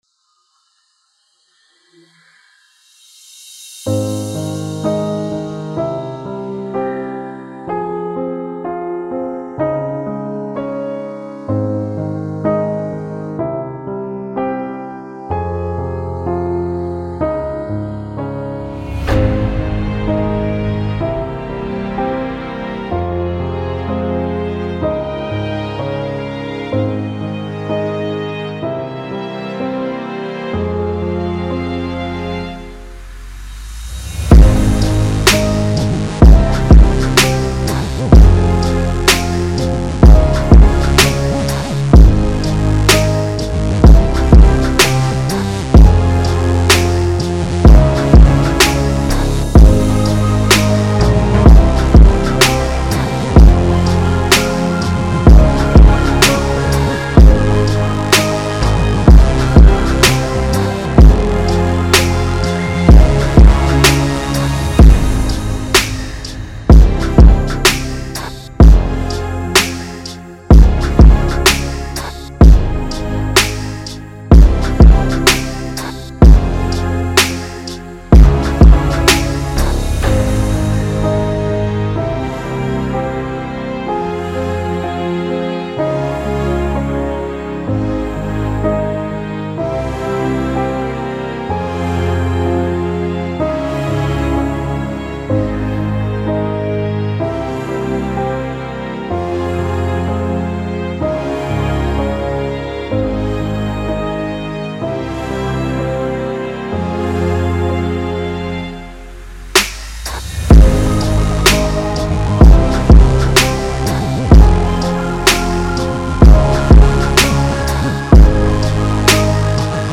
Pop, R&B
F Min